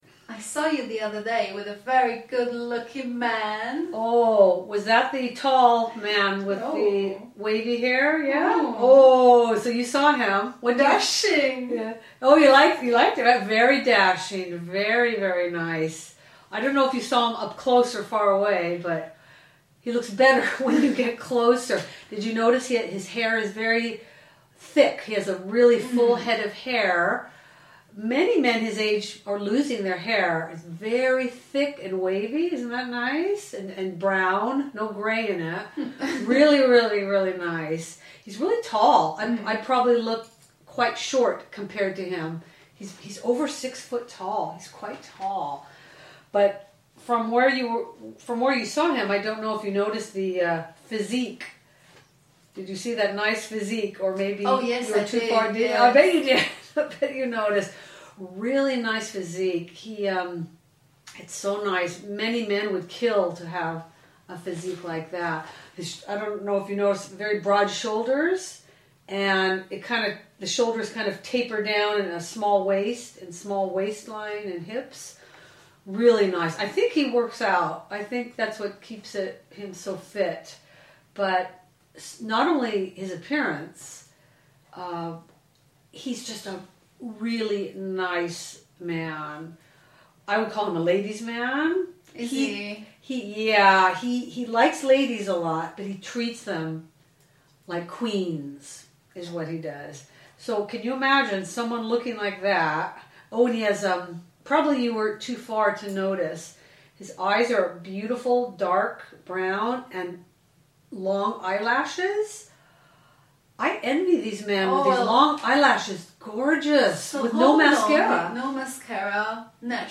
Housewives´ Gossips audiokniha
Housewives´ Gossips - audiokniha obsahuje konverzační obraty v angličtině v interpretaci rodilých mluvčích.
Ukázka z knihy